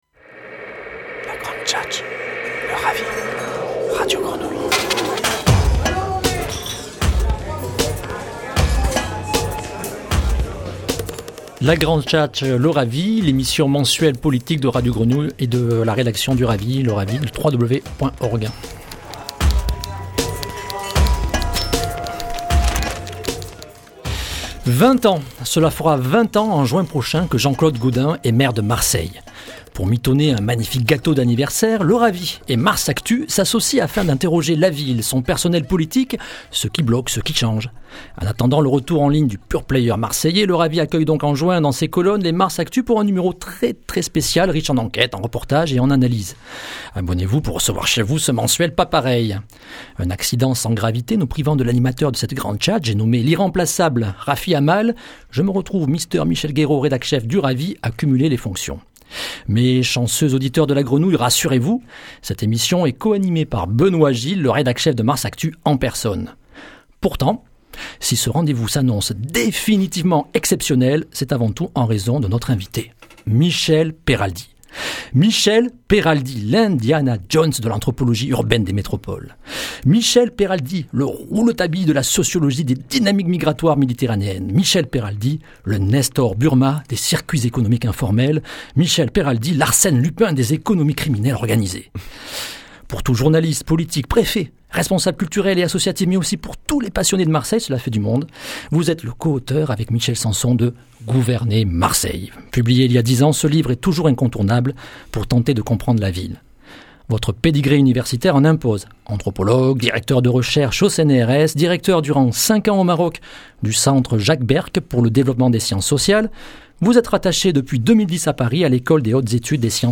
Entretien en partenariat avec Radio Grenouille